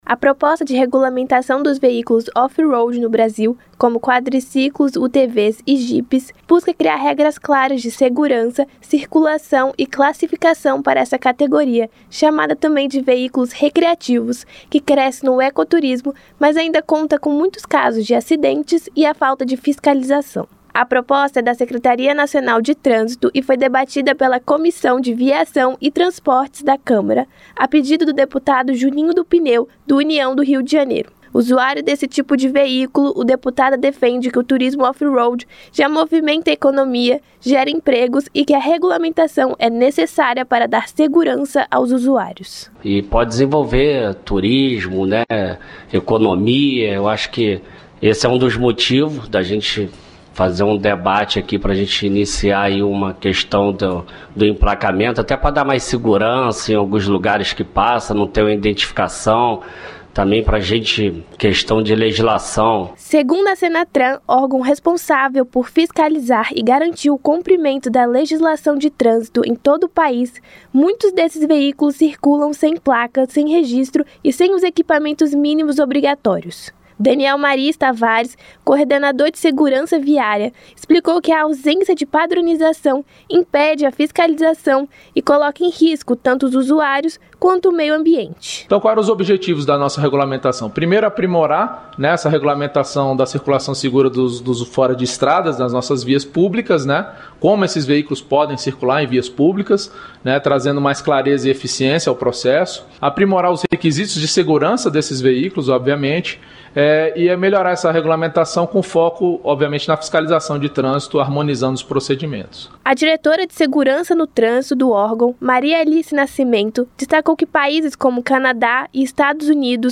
Debatedores discutem regulamentação dos veículos off-road no Brasil - Radioagência